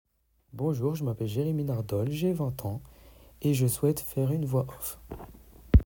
Voix off
Scientifique russe (humour)